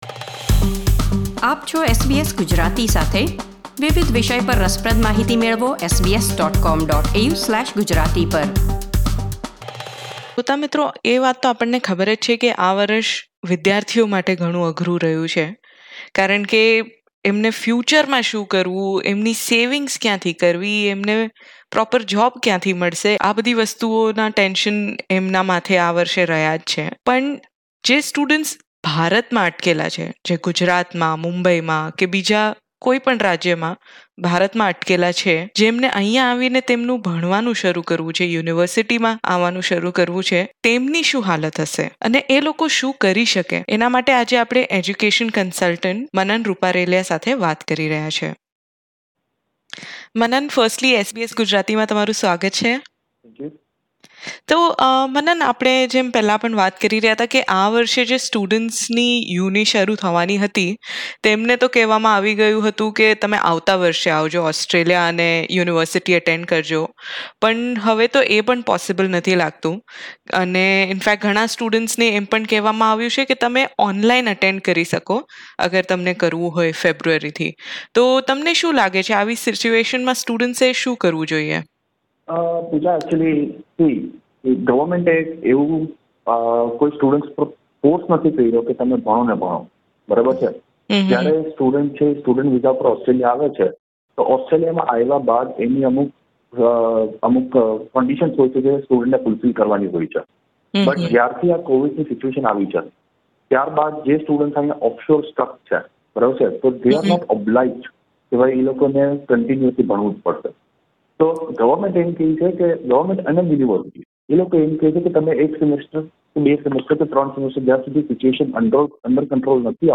** ઇન્ટરવ્યુંમાં આપવામાં આવેલી માહિતી સામાન્ય સંજોગોને આધારિત છે.